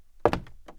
woodFootstep05.wav